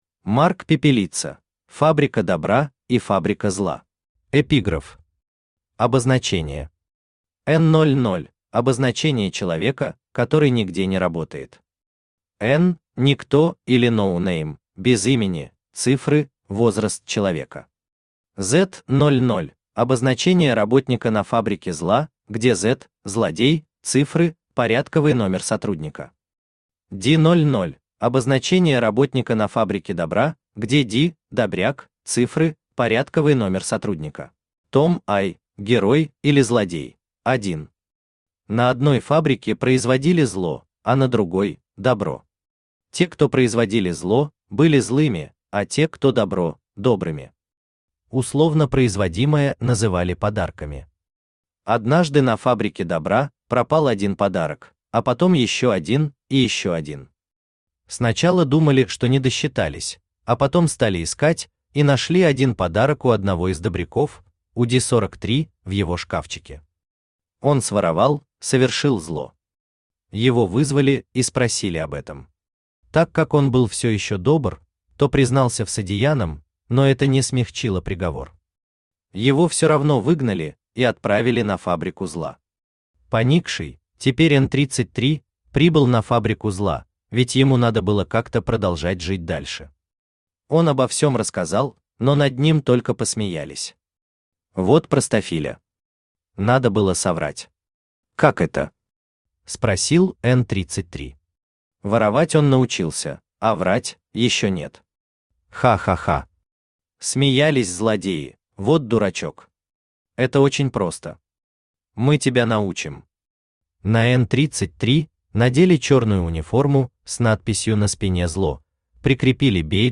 Аудиокнига Фабрика Добра и Фабрика Зла | Библиотека аудиокниг
Aудиокнига Фабрика Добра и Фабрика Зла Автор Марк Пепелица Читает аудиокнигу Авточтец ЛитРес.